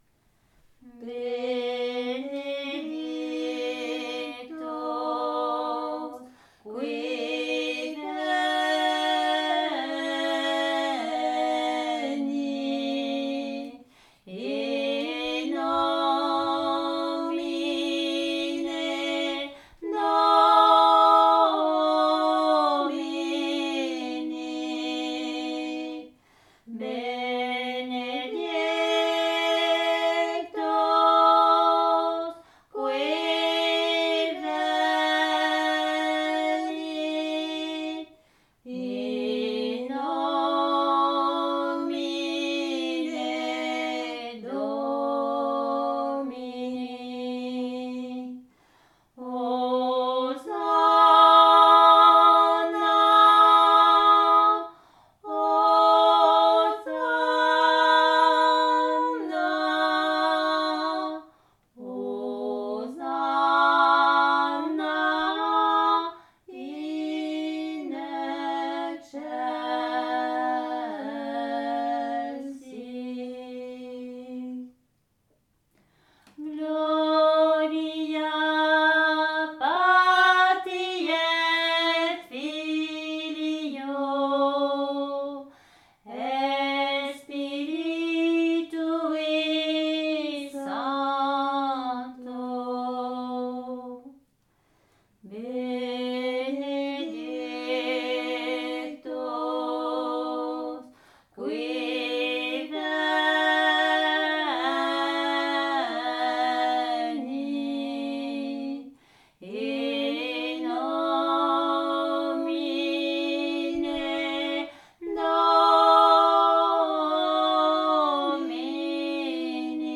Aire culturelle : Bigorre
Lieu : Ayros-Arbouix
Genre : chant
Effectif : 2
Type de voix : voix de femme
Production du son : chanté
Classification : cantique